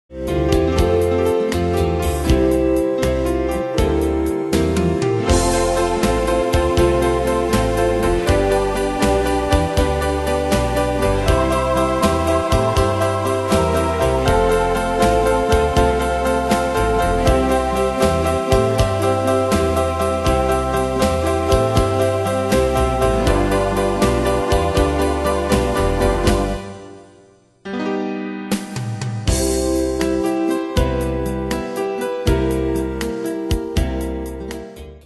Style: PopFranco Ane/Year: 1994 Tempo: 80 Durée/Time: 3.37
Danse/Dance: Ballade Cat Id.
Pro Backing Tracks